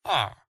Minecraft / mob / villager / idle3.ogg